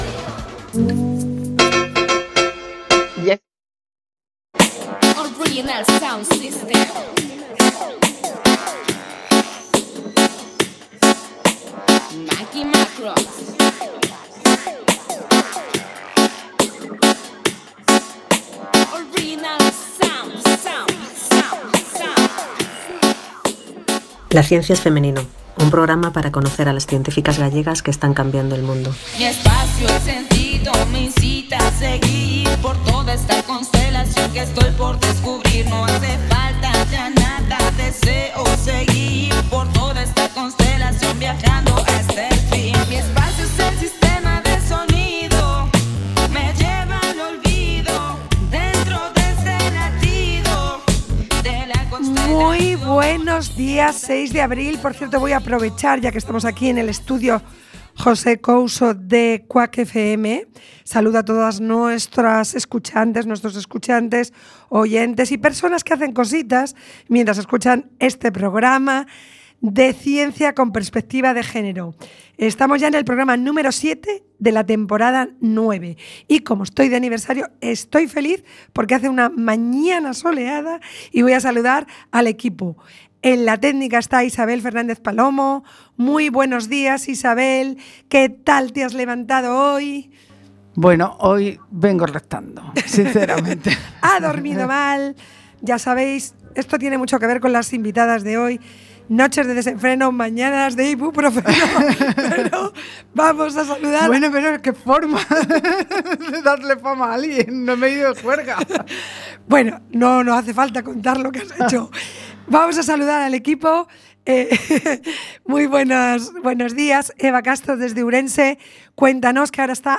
En este programa de radio, cada 15 días, durante una hora, de 12.00 a 13.00, en domingos alternos, Entrevistamos y damos voz a las científicas gallegas que trabajan hoy en la ciencia.
Localizaciones: Estudio José Couso- Cuac FM.